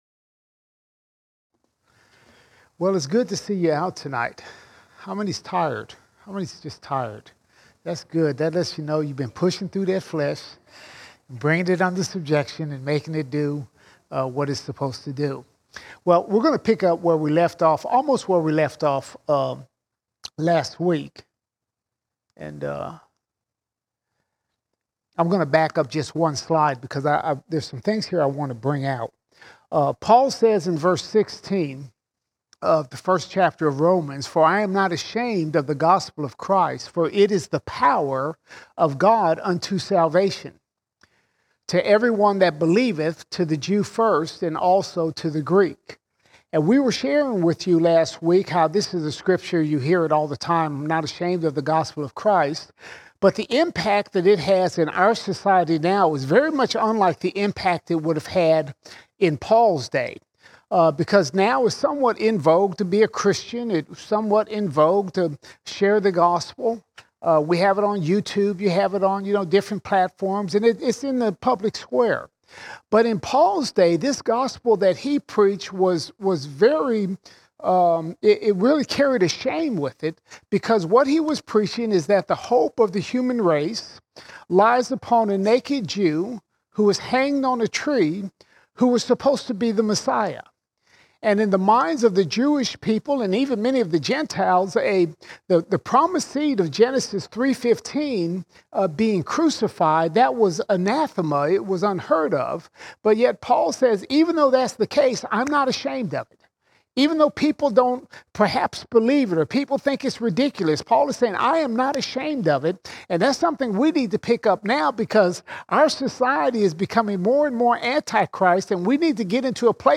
22 June 2023 Series: Romans All Sermons Romans 1:17 to 1:32 Romans 1:17 to 1:32 Paul lays out the argument why men are sinners and are under God's wrath.